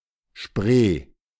The Spree (/ʃpr, spr/ S(H)PRAY, German: [ʃpʁeː]